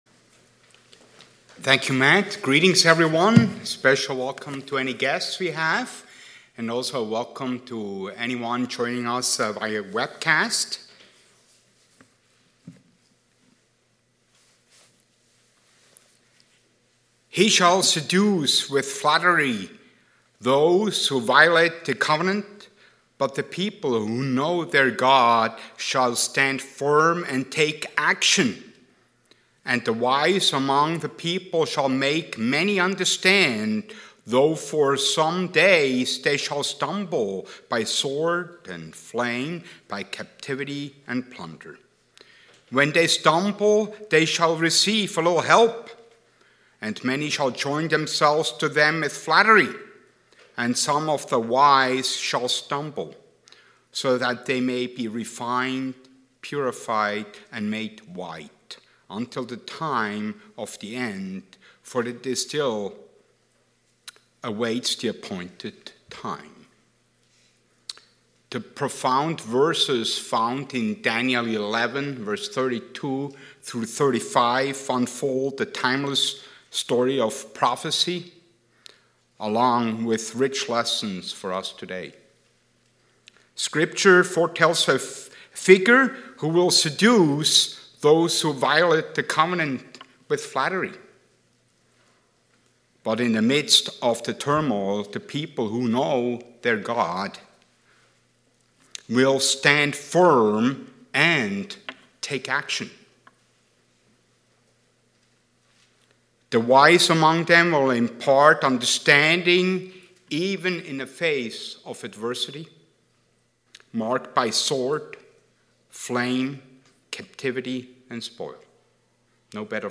The sermon delves into the timeless relevance of Bible prophecy, focusing on Daniel 11 and its applicability to today's trials. It explores God's sovereignty over global events, using historical contexts such as Antiochus IV Epiphanes to illustrate the dual aspects of prophecy and God's personal involvement.